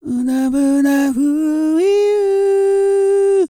E-CROON 3041.wav